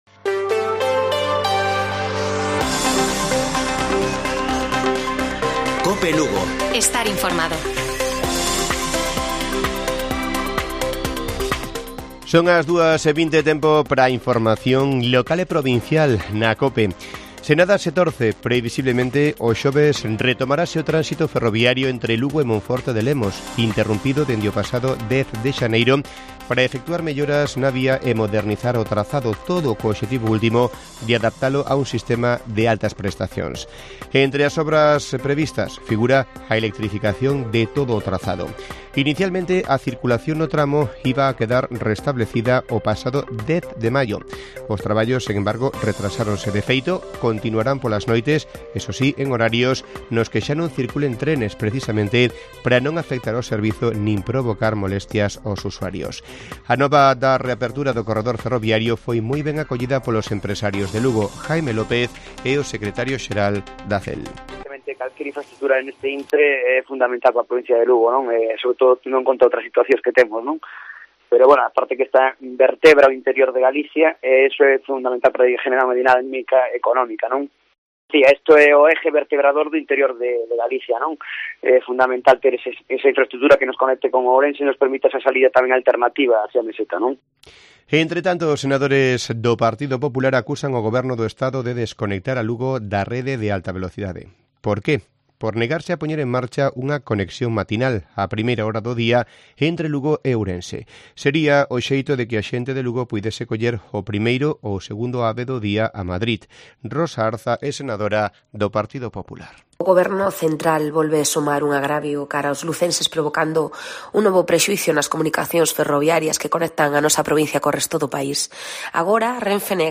Informativo Mediodía de Cope Lugo. 28 DE JUNIO. 14:20 horas